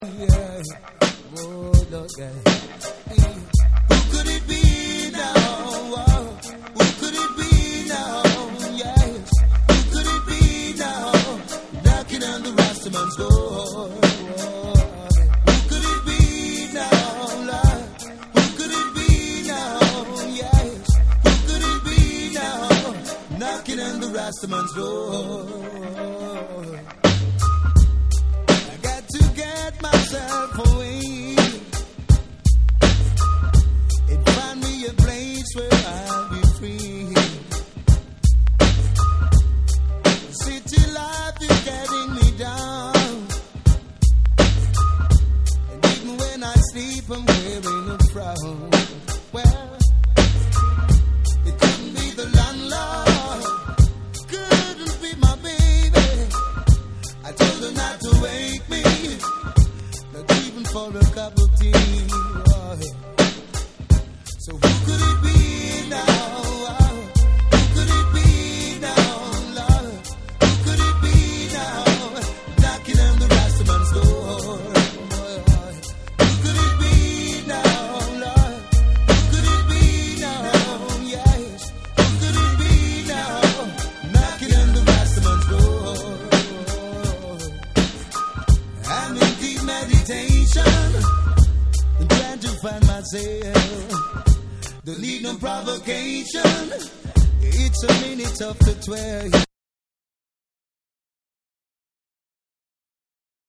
BREAKBEATS / ALL 840YEN